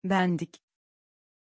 Prononciation de Bendik
pronunciation-bendik-tr.mp3